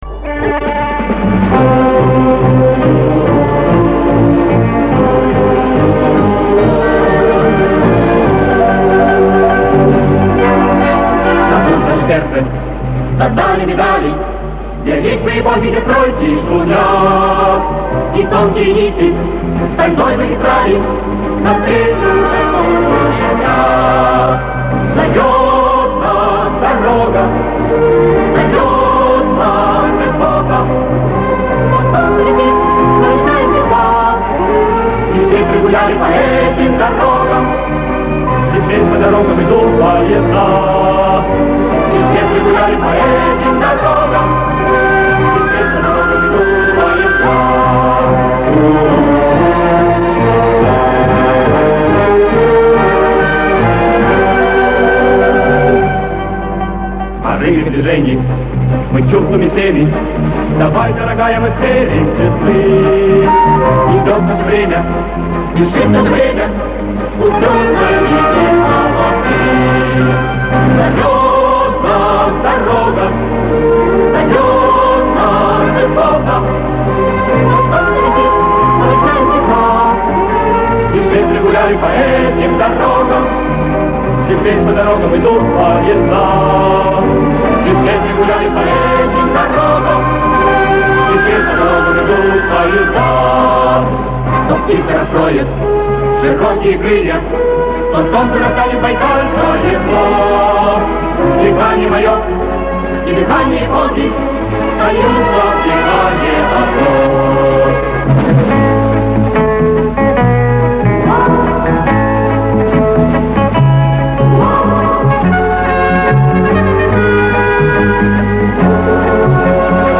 Тут женский вокал присутствует
Получается редкая запись, да и почище будет.